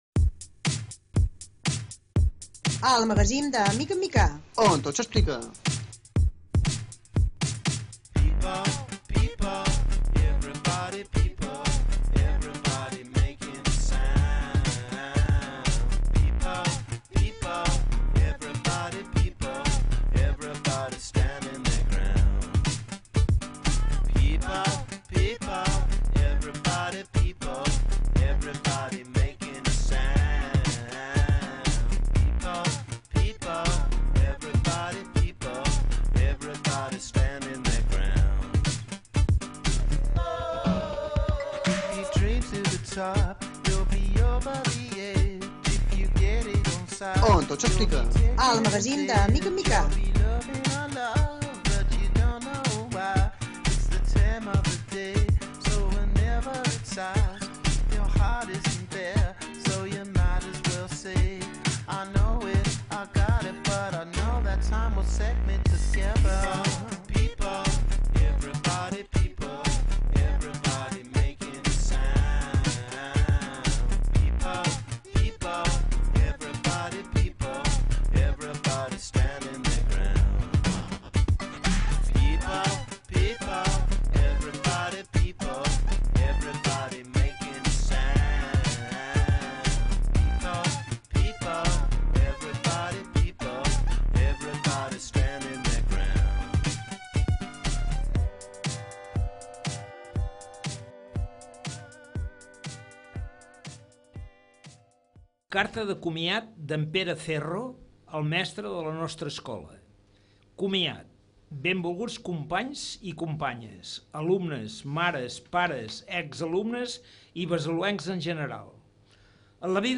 Sintonia i lectura de la carta de comiat
Entreteniment